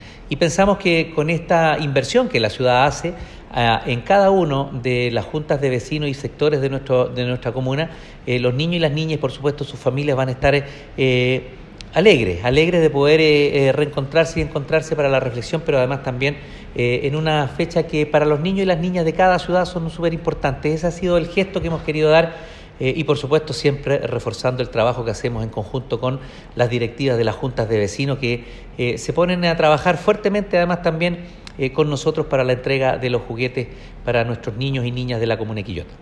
Alcalde-Juguetes.m4a